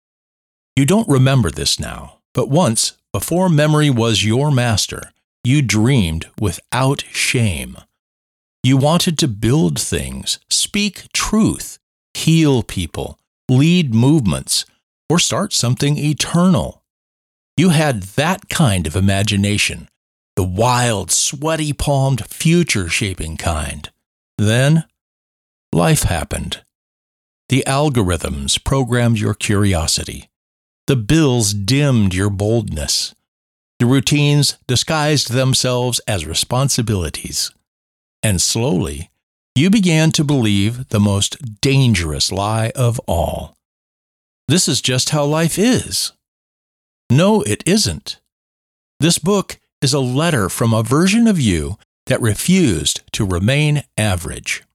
Authoritative/Conversational